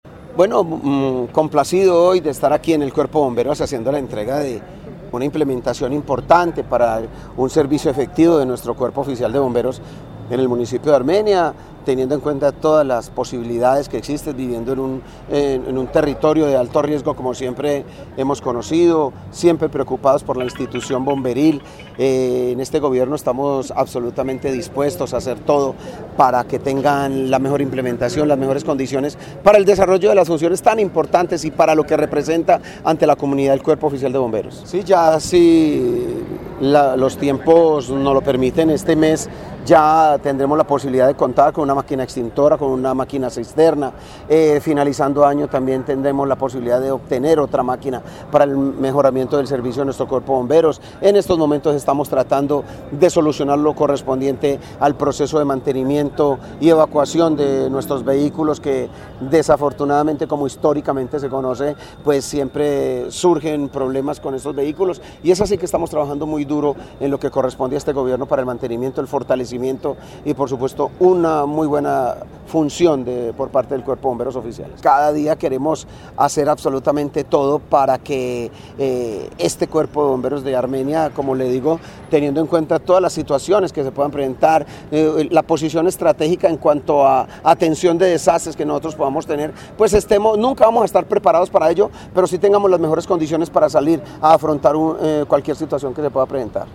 Alcalde de Armenia